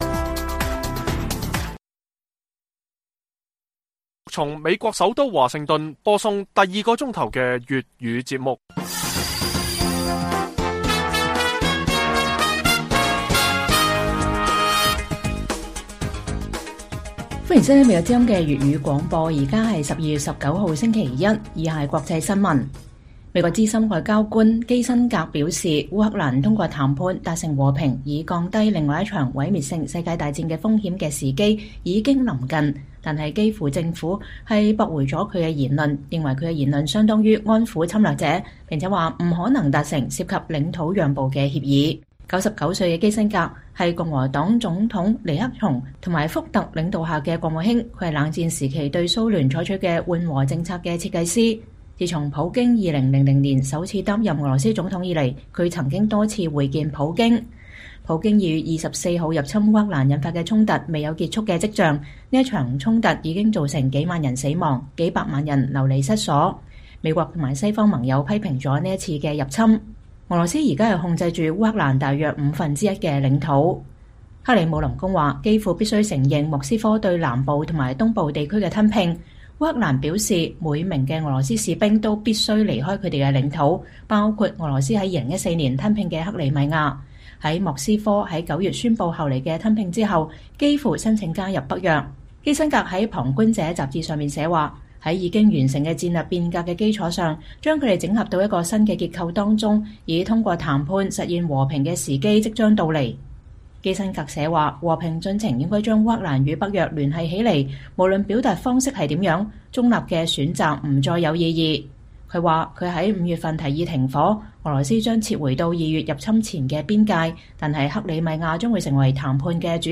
粵語新聞 晚上10-11點：基輔拒絕基辛格建議烏克蘭通過談判實現和平